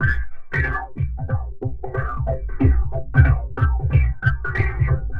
All these loops were created at loopasonic and are all original and copyright free.
FROOTY SHUFFLE FX (449Kb)